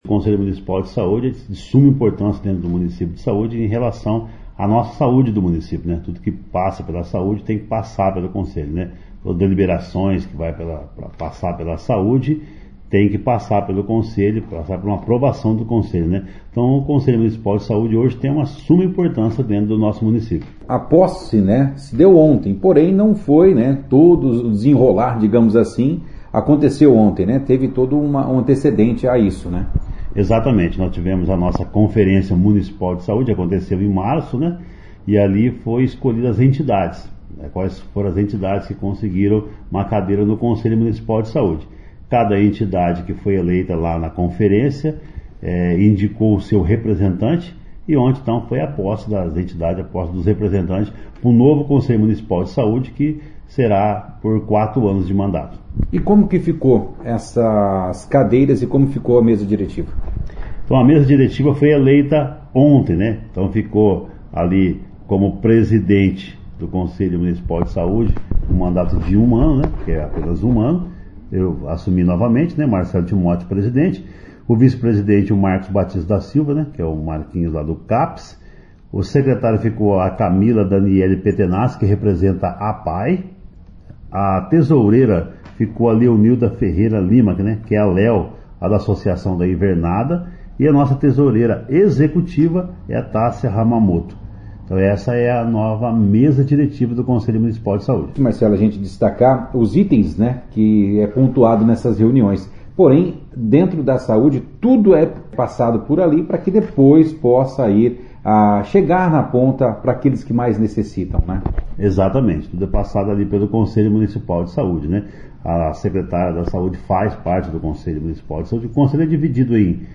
participou da 2ª edição do jornal Operação Cidade desta quinta-feira, 20